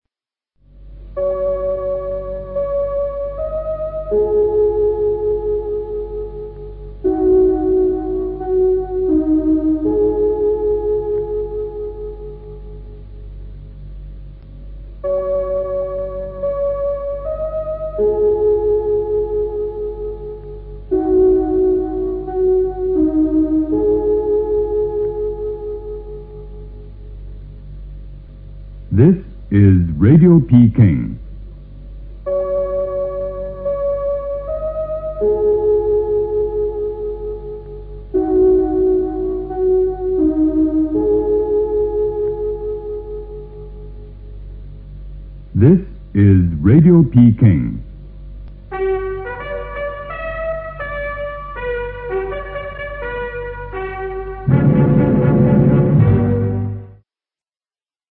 • 电风琴演奏版本，开头两小节（1950-1965）[11]